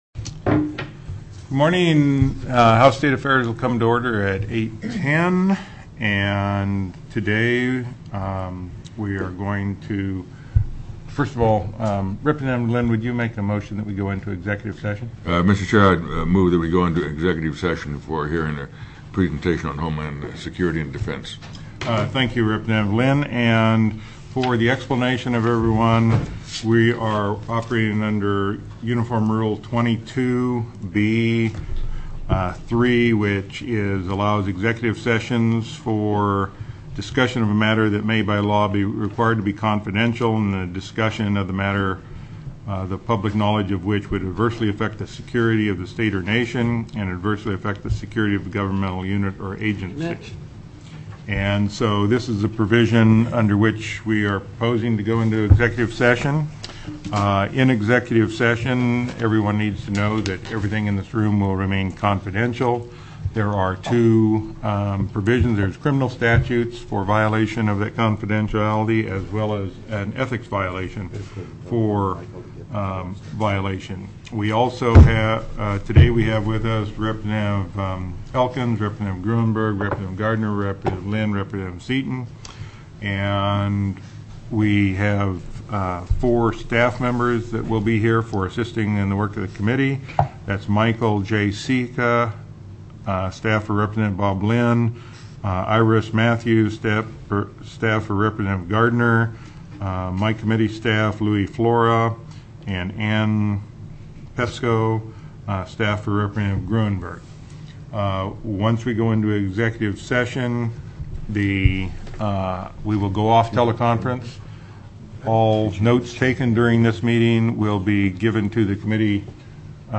04/25/2006 08:00 AM House STATE AFFAIRS
+ Division of Homeland Security TELECONFERENCED